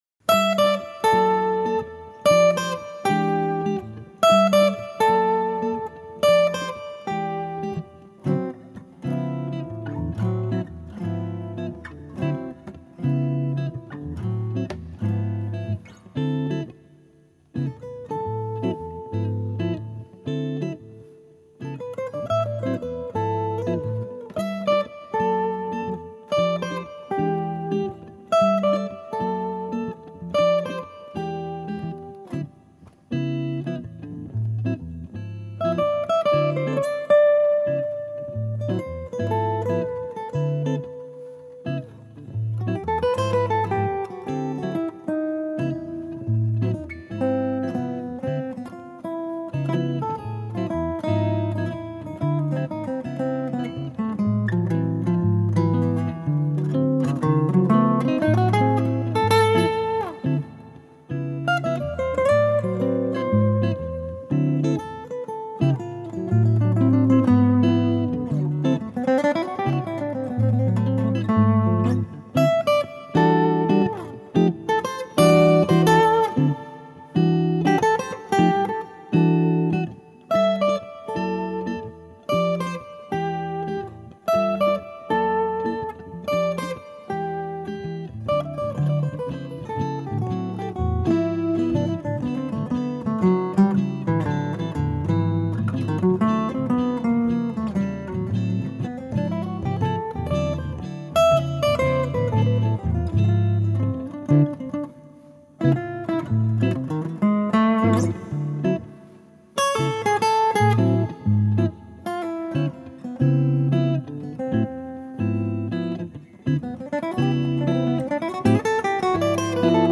(Gitarren-Duo)
(instrumental)
(Acoustic-, Semiacoustic- und E-Gitarren)